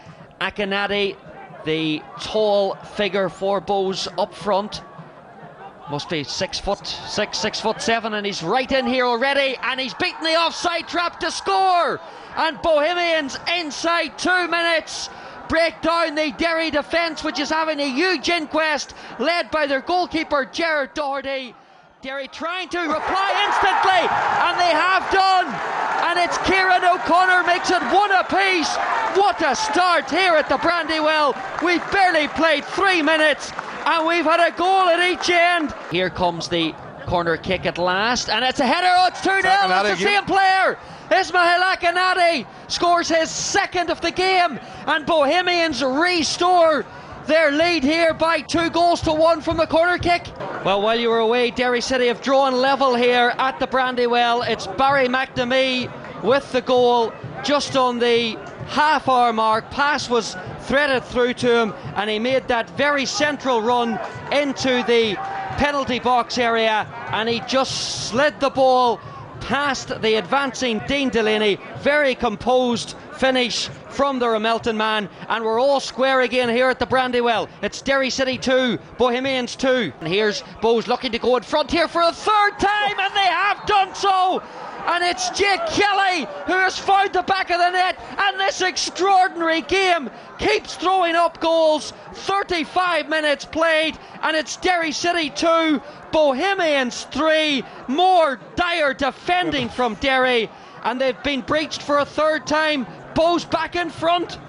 LISTEN: Hear the goals from last night's five goal thriller at Brandywell as Derry lose narrowly 3-2 at home to Bohemians